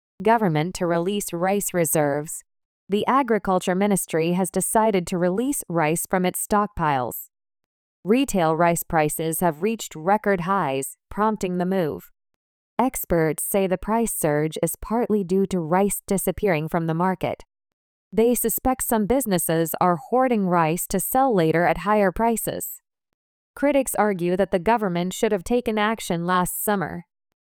【ナチュラルスピード】